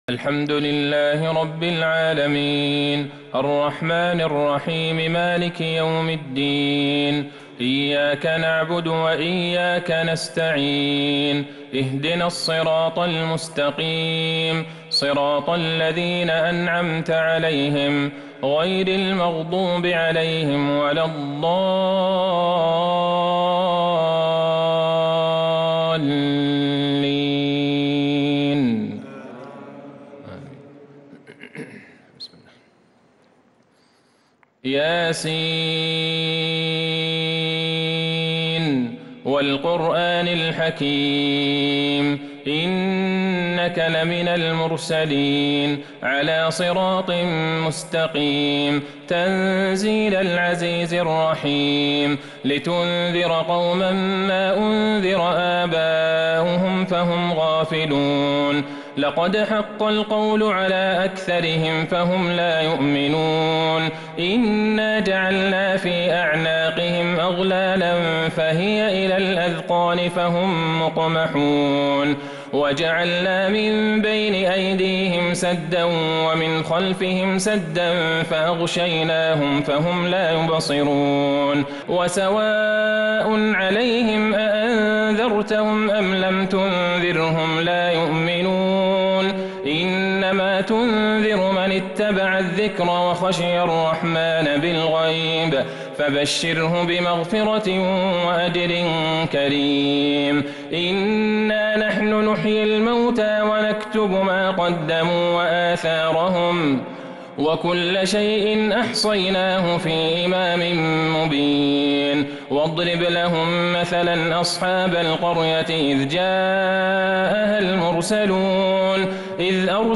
صلاة التراويح | ليلة ٢٥ رمضان ١٤٤٢هـ | سورة يس كاملة | Tahajjud 25st night Ramadan 1442H > تراويح الحرم النبوي عام 1442 🕌 > التراويح - تلاوات الحرمين